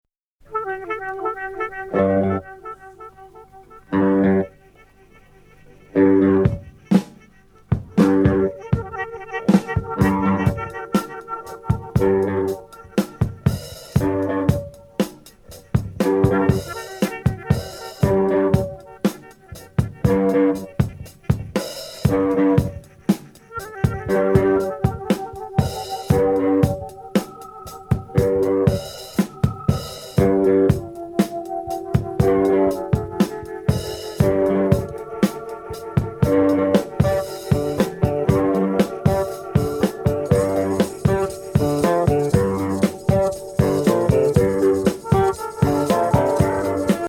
コズミック・ガレージィな異次元ジャム!